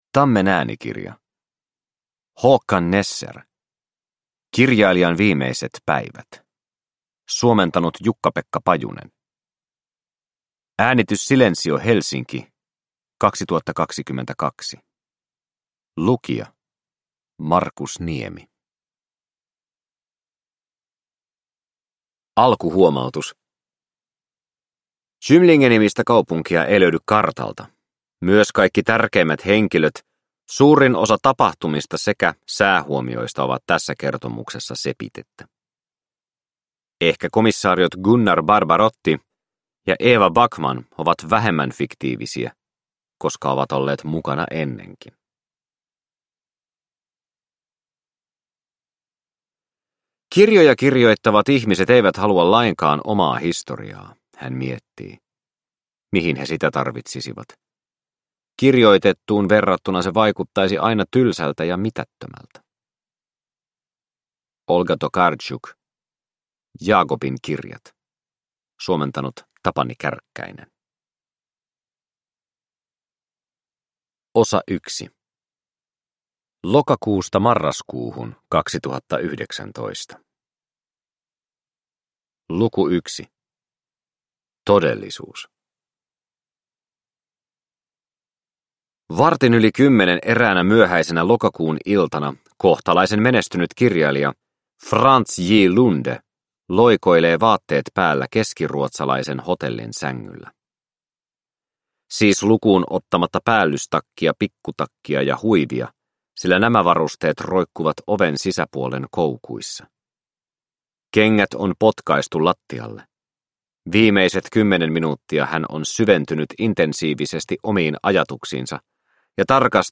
Kirjailijan viimeiset päivät – Ljudbok – Laddas ner